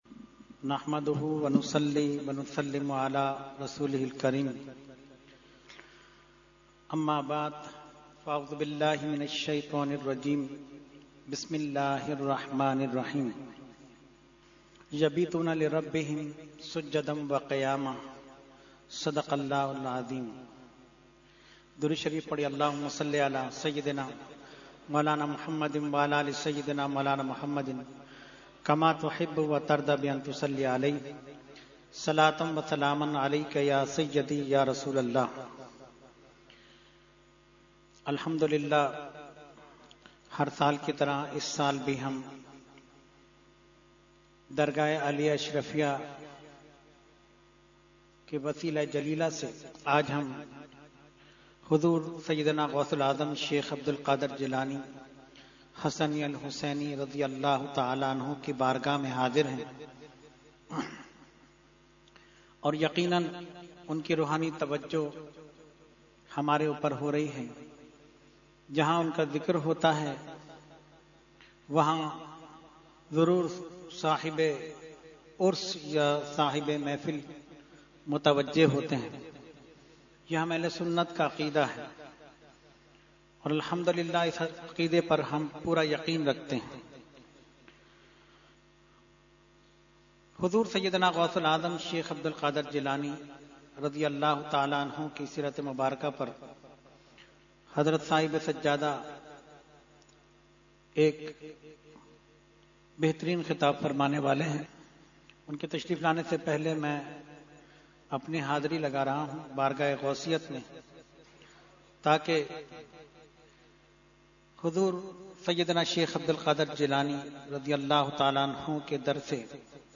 Category : Speech | Language : UrduEvent : 11veen Shareef 2018-2